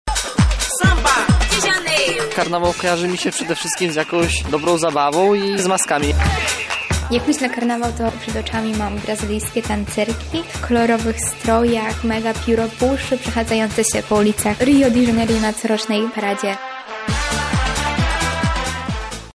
O skojarzenia z odległą Brazylią zapytaliśmy studentów:
Rio- sonda